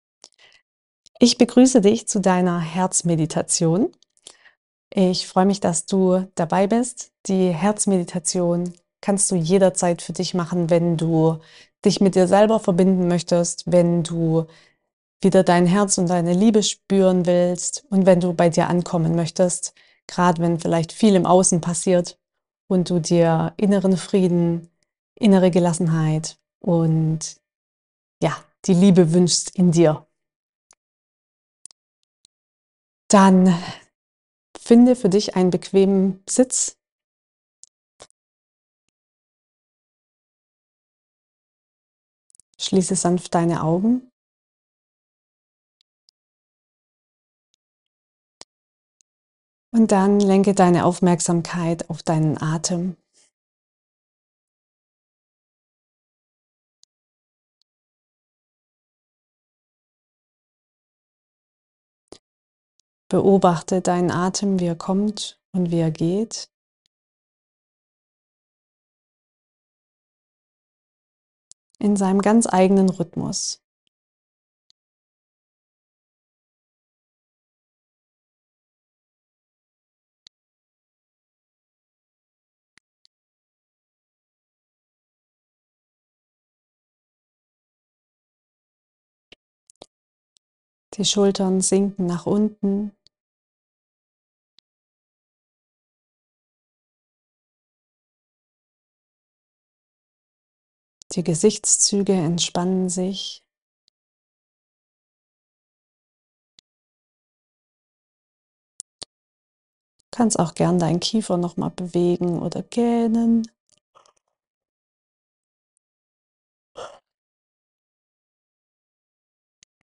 Herzmeditation: Bei dir ankommen
Du wirst sanft in deinen Herzraum geführt, kannst dich ausdehnen, deine eigene Liebe, Wärme und Lebendigkeit spüren.